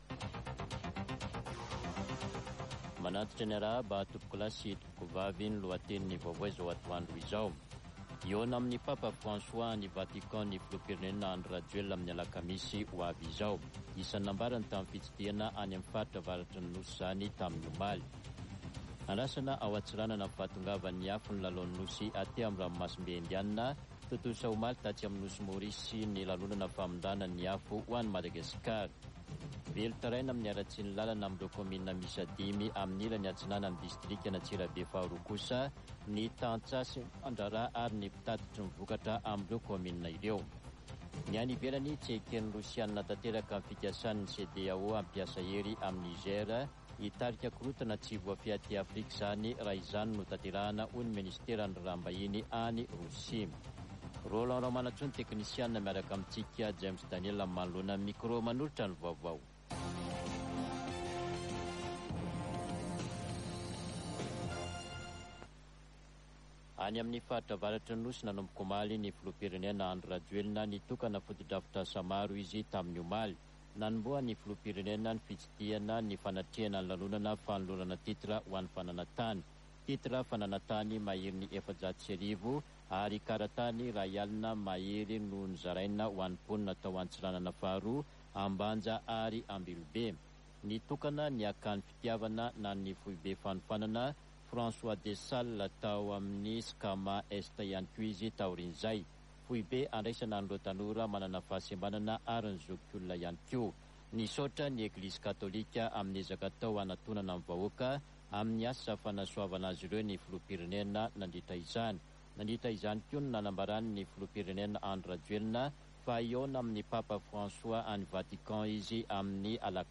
[Vaovao antoandro] Sabotsy 12 aogositra 2023